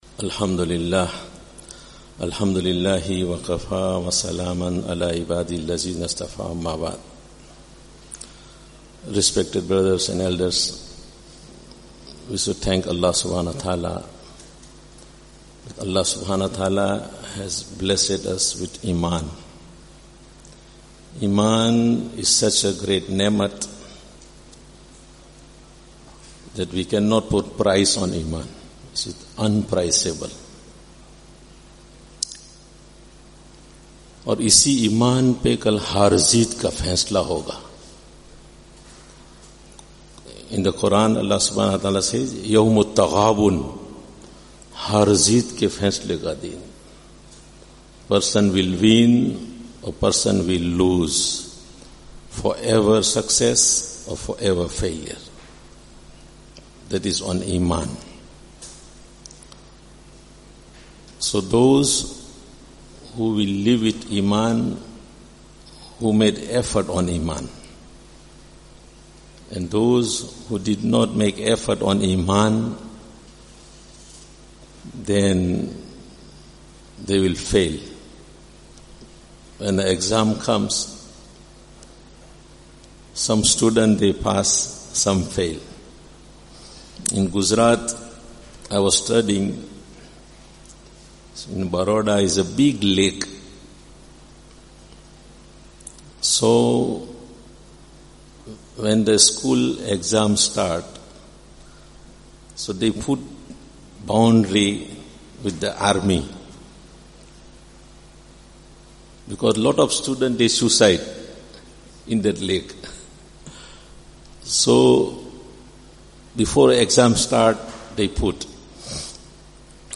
After Fajr Bayaan